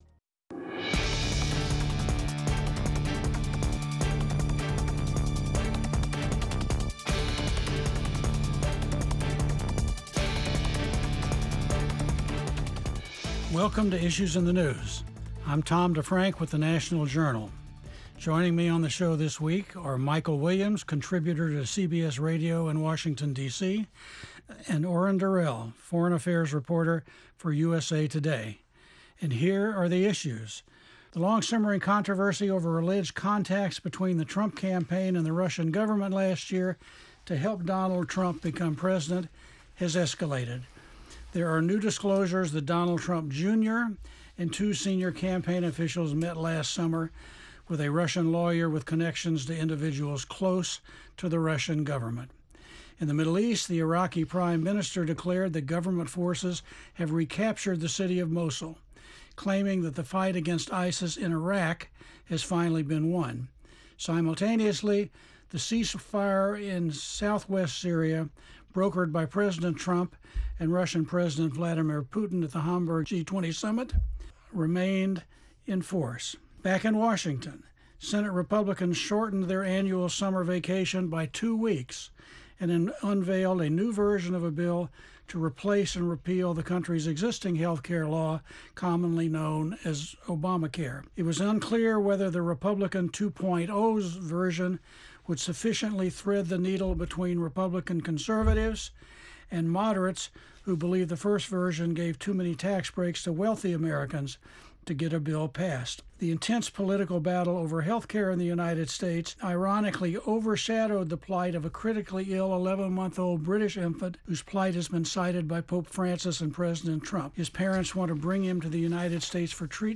Journalists and analysts discuss the week's top stories including the latest about the meeting between a Russian attorney and President Donald Trump's son.